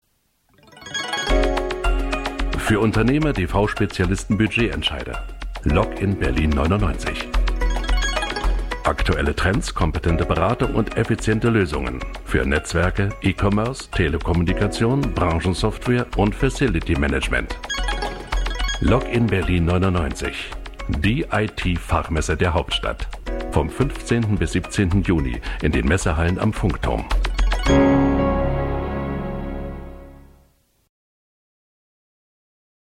tiefe Stimme=WERBUNG:Bier,Bundeswehr, Ergo,Stadt München-DRAMA:Hörbuch, Kirchenlyrik-DOK:Jüdi. Museum-Leitstimme-COMIC:Paradiso-
Sprechprobe: Sonstiges (Muttersprache):